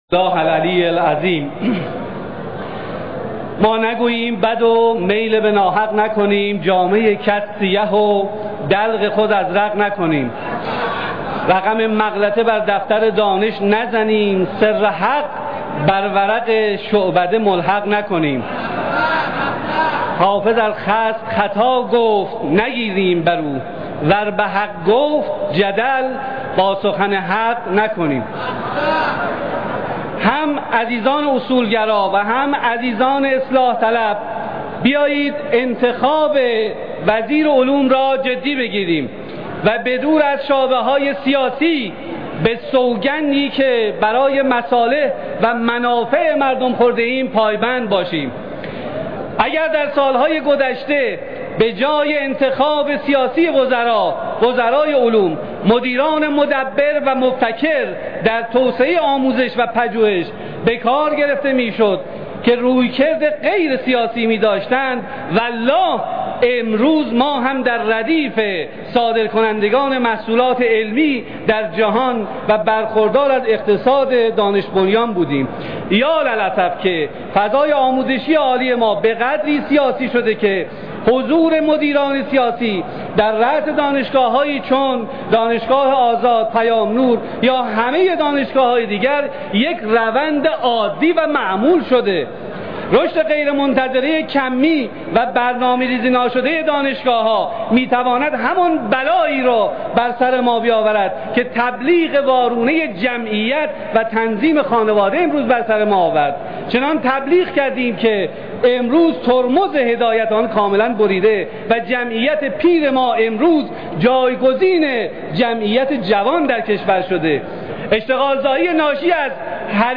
به گزارش «نماینده» به نقل از فارس، جواد هروی نماینده مردم قائنات در مجلس شورای اسلامی در جلسه علنی امروز (یکشنبه پارلمان) در مخالفت با رضا فرجی دانا وزیر پیشنهادی علوم، تحقیقات و فناوری گفت: هم اصولگرایان و هم اصلاح‌طلبان انتخاب وزیر علوم را جدی گرفته و با سوگند خود به وزیر پیشنهادی رأی دهند.